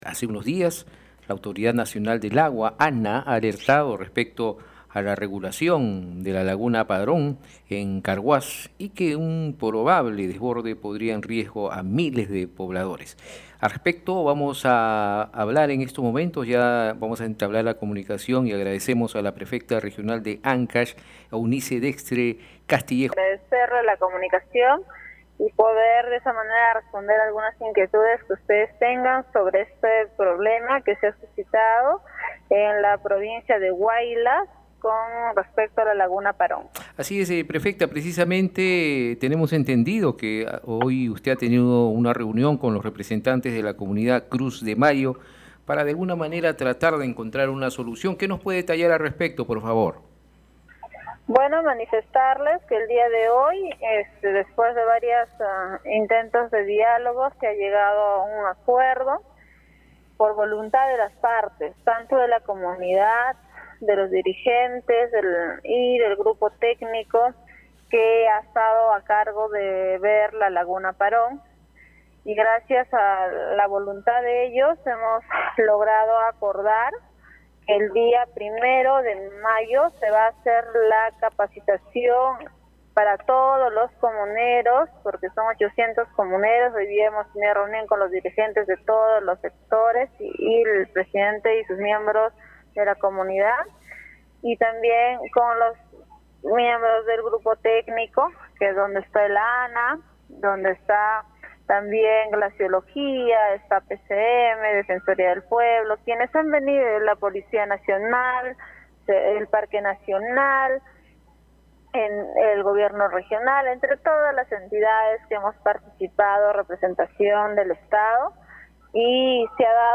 La prefecta regional de Ancash, Eunice Dextre Castillejo, informó a RCR, Red de Comunicación Regional, que el acuerdo se consiguió tras un prolongado diálogo entre los dirigentes de la comunidad y el grupo técnico integrado por la Autoridad Nacional del Agua (ANA), Presidencia del Consejo de Ministros, Defensoría del Pueblo, Policía y Gobierno Regional de Ancash, entre otras instituciones.